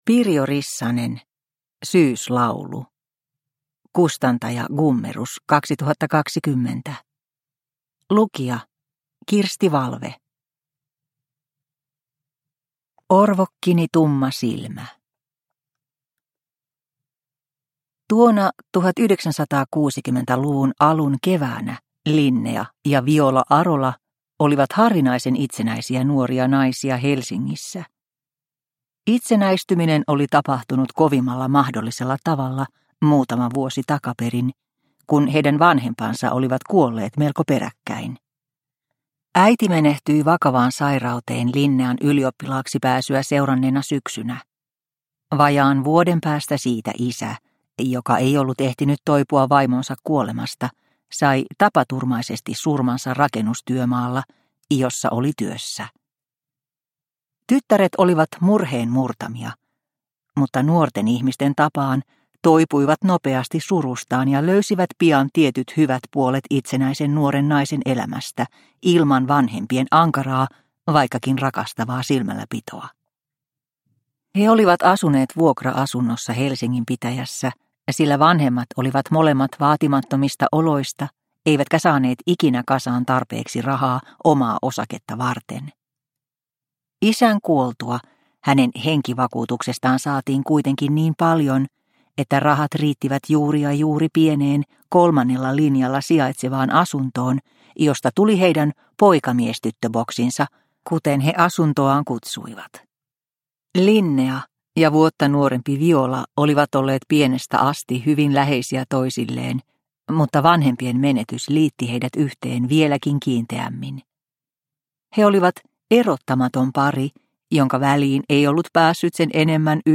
Syyslaulu – Ljudbok – Laddas ner